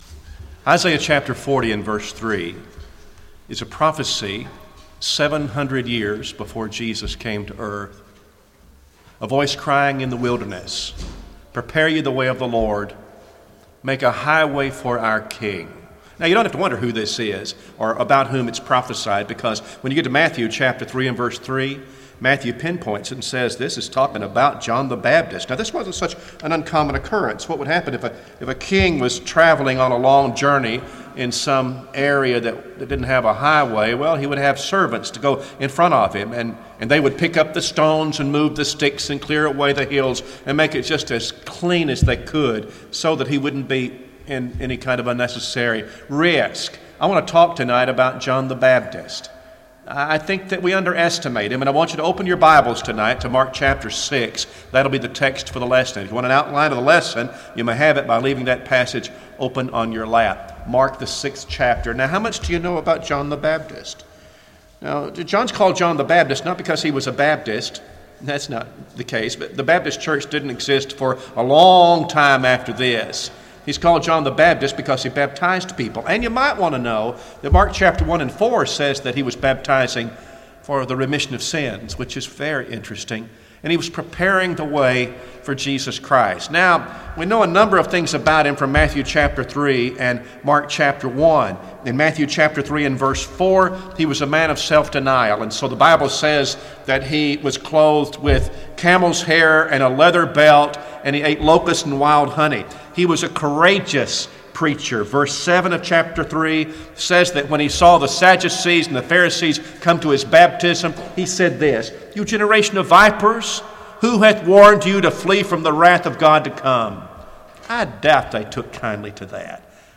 Service Type: Gospel Meeting